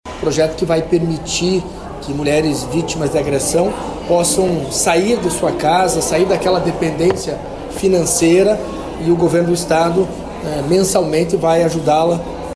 O presidente da Assembleia Legislativa do Paraná, Alexandre Curi (PSD), falou sobre o apoio financeiro garantido pelo estado.